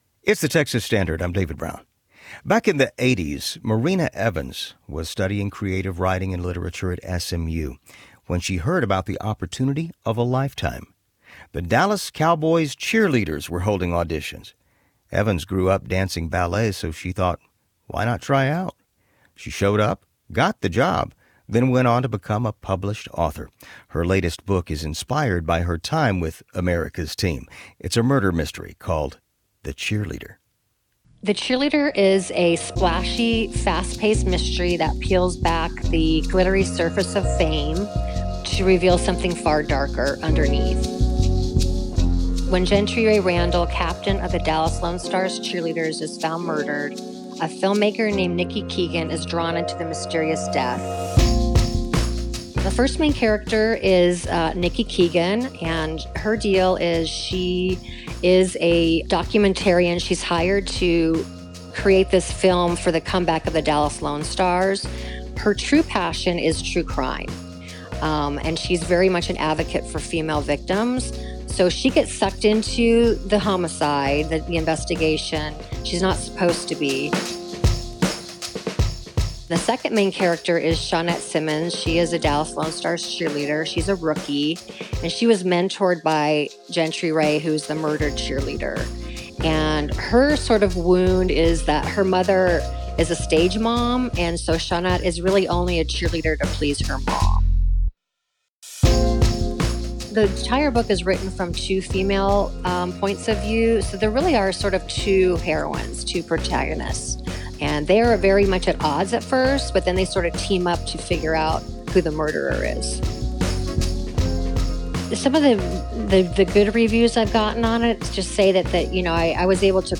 Latest interview with Texas Standard Radio